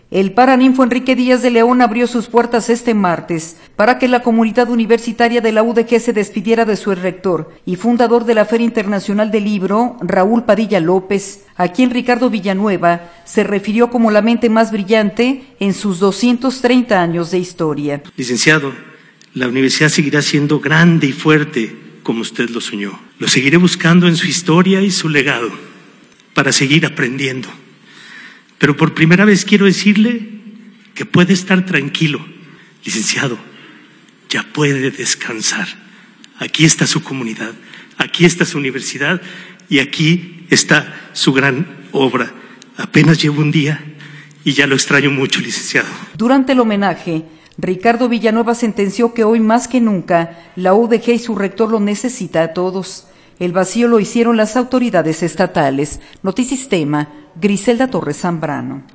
Durante el homenaje, Ricardo Villanueva sentenció que hoy más que nunca la UdeG y su rector los necesita a todos.